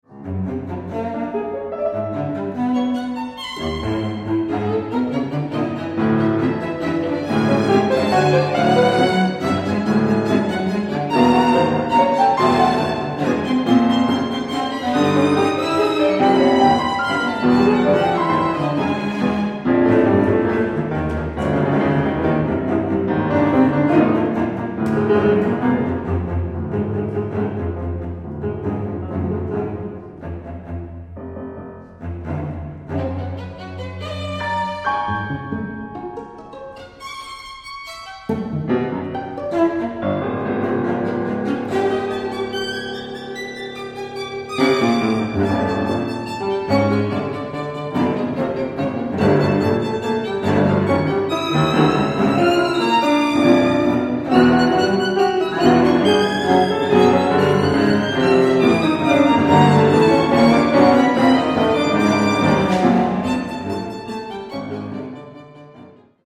violin, Cello, Piano